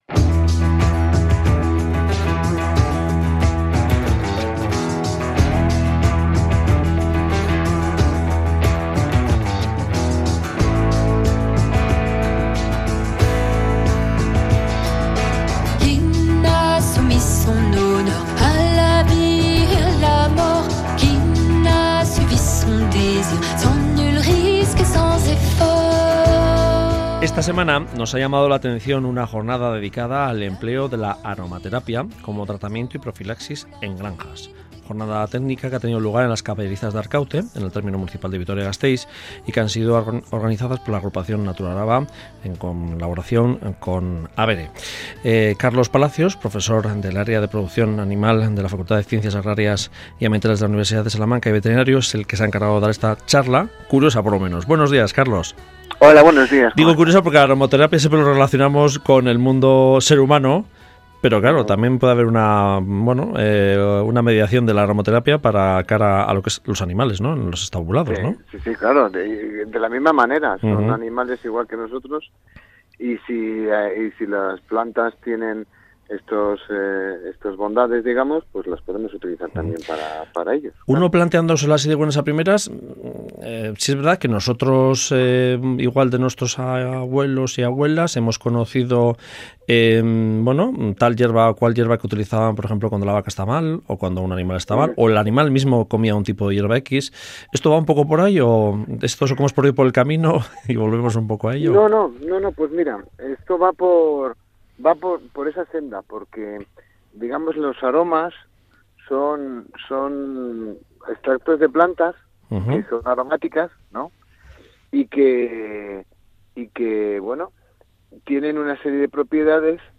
Charla ofrecida en Arkauti (Araba)